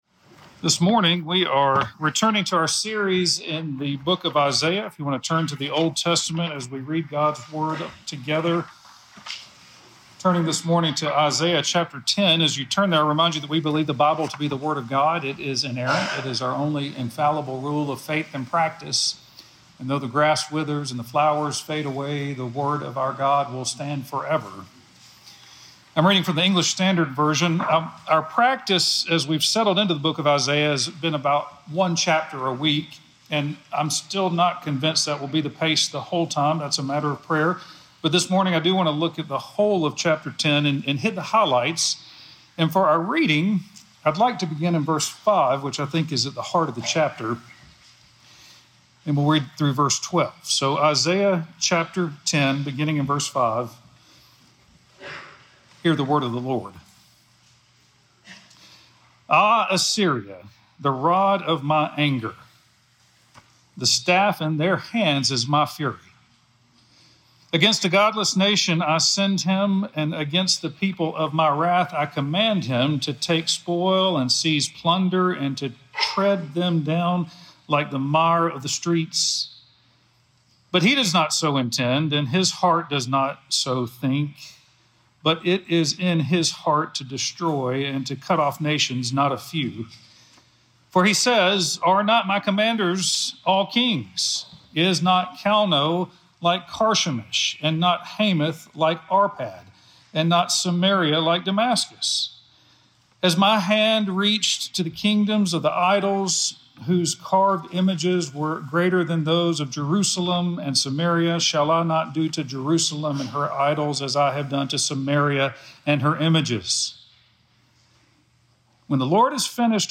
Listen to The Arrogant Heart sermon published on Jan 4, 2026, by Trinity Presbyterian Church in Opelika, Alabama.